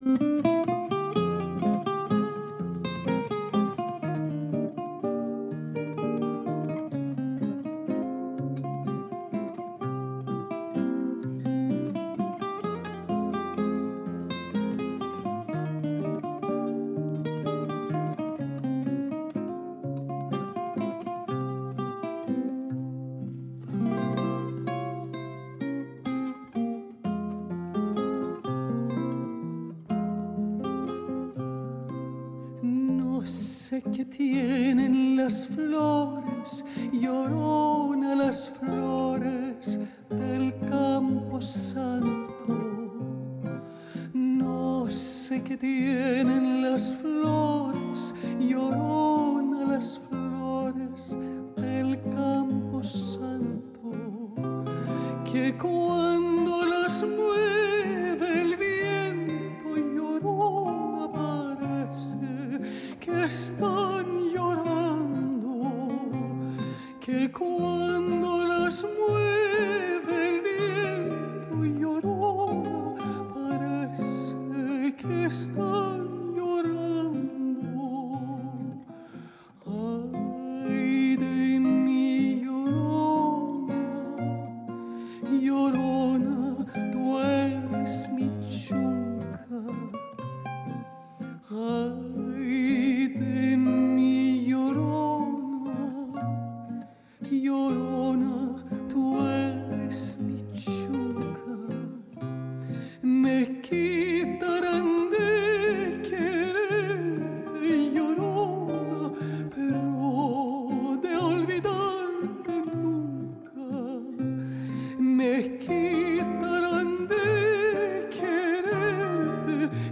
Αλεξάνδρα Γκράβας: Η διάσημη Ελληνίδα μετζοσοπράνο στο στούντιο της Φωνής της Ελλάδας, λίγες μέρες πριν τη συναυλία της στη Θεσσαλονίκη